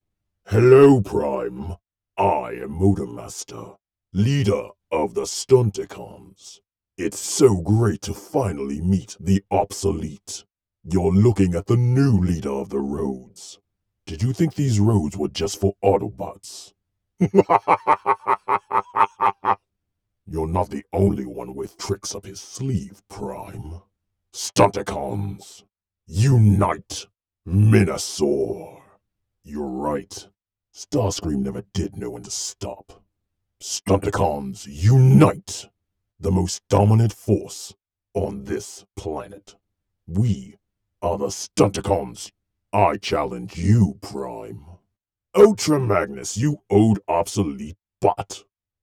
MotorMaster Audition - Processed